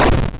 EnemyDeath.wav